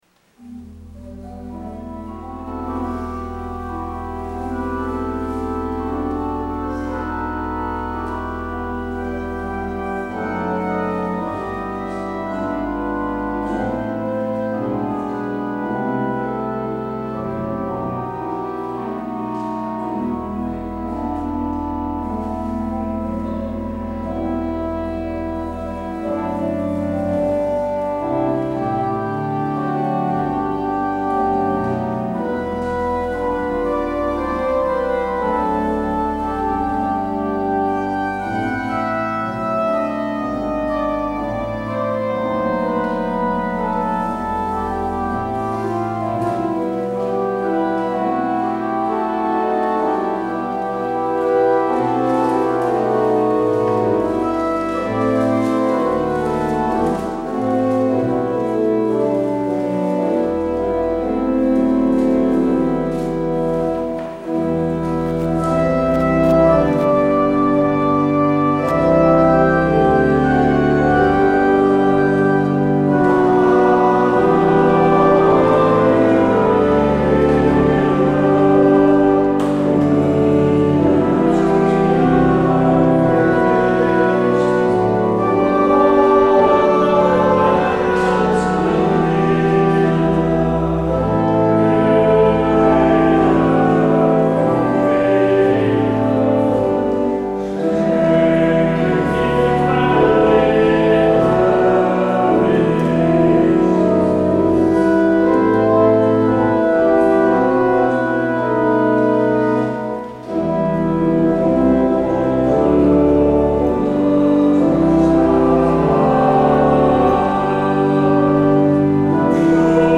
 Luister deze kerkdienst terug
Jezus wijst ons een andere weg. Als openingslied kunt u luisteren naar Lied 130c (Uit de diepten).